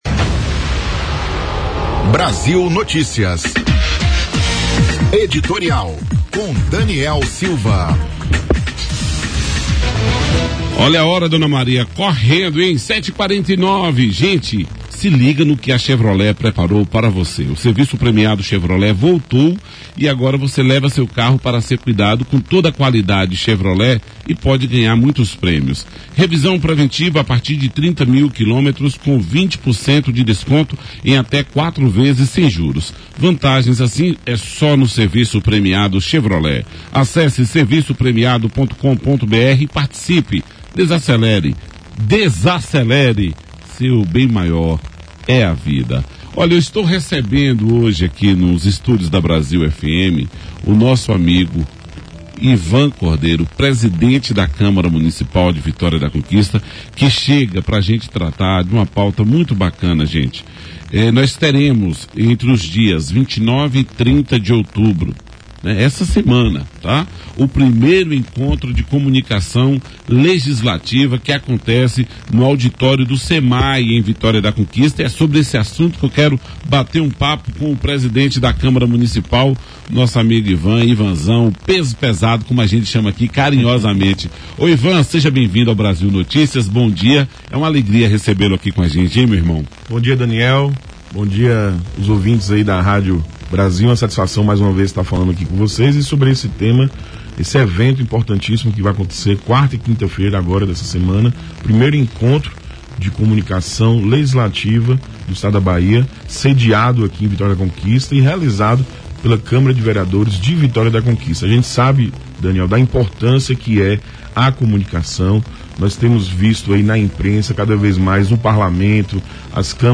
O presidente da Câmara Municipal de Vitória da Conquista, Ivan Cordeiro da Silva Filho, do Partido Liberal, participou do Brasil Notícias (Rádio Brasil)